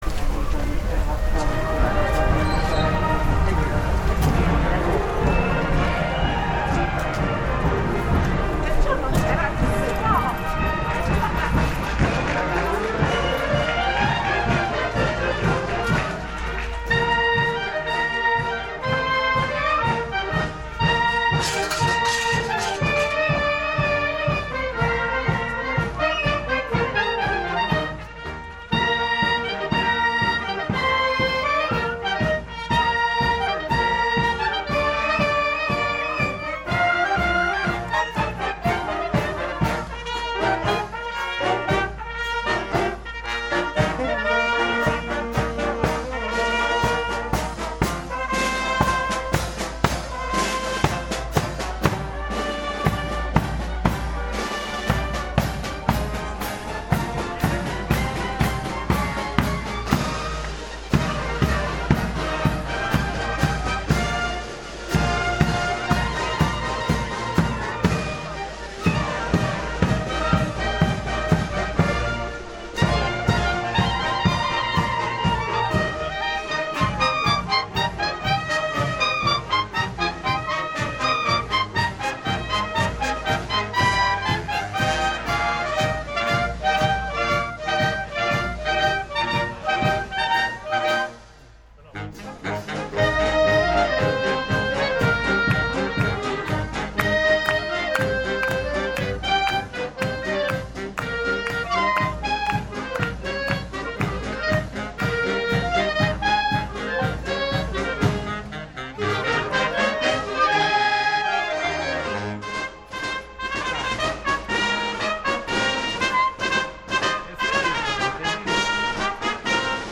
le parole di circostanza pronunciate dal locale Sindaco hanno fatto da prologo a ciò che il vostro cronista stava attendendo: l’esibizione della banda costituita presso il liceo musicale Giuseppe Maria galanti che ha eseguita in maniera impeccabile
la marcia ‘Mosè’ di Gioacchino Rossini, pezzo che ha conclusa la manifestazione, ma soprattutto ha dimostrato che quando la scuola vuole, sa esser tale.